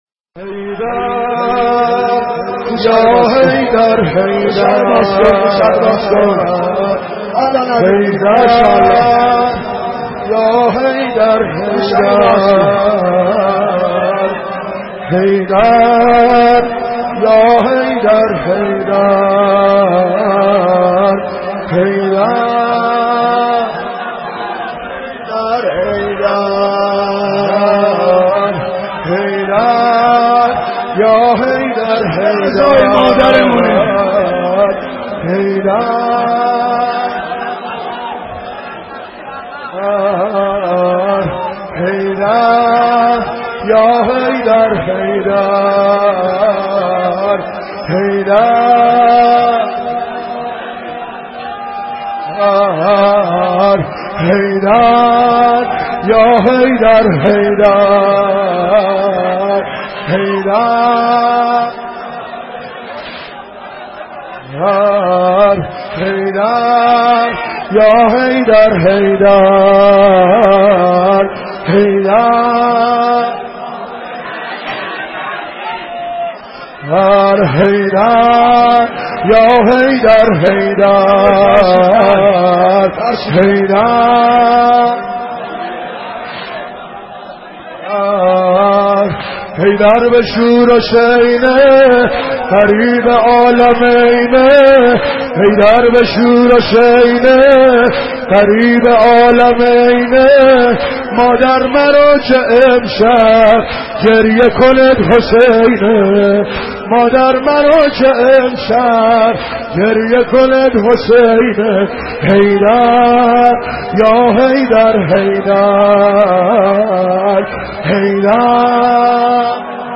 دانلود مداحی حید یا حیدر - دانلود ریمیکس و آهنگ جدید
مراسم نوحه خوانی در سوگ شهادت حضرت زهرا (س) با صدای عبدالرضا هلالی (8:11)